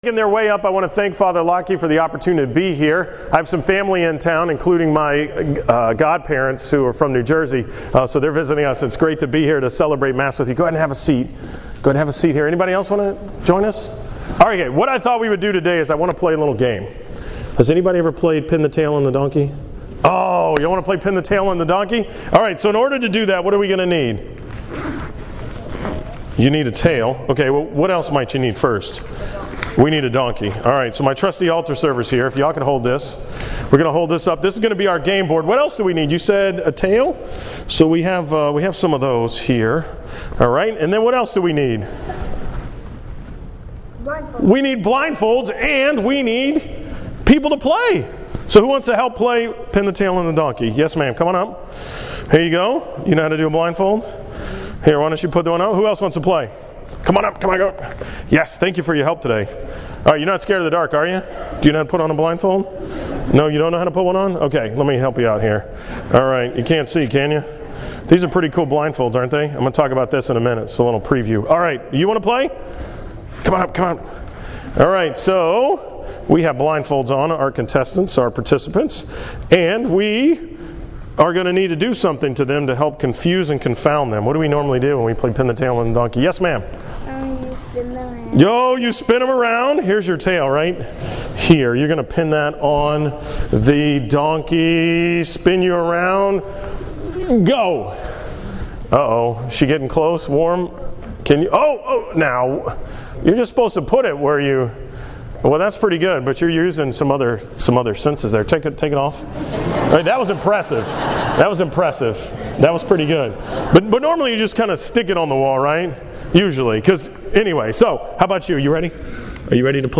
From the 8:30 am Mass at St. Elizabeth Ann Seton on March 15, 2015